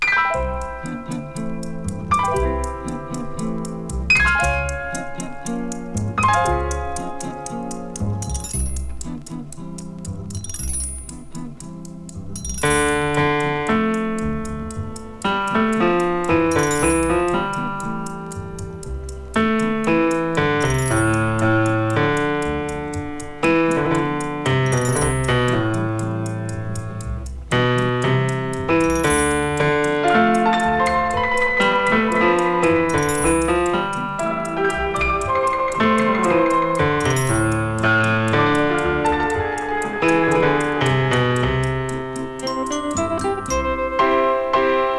ステレオ録音技術の躍進もあり、スピーカーの片方ずつにピアノを配置。
味わいと深みある音が素晴らしい作品です。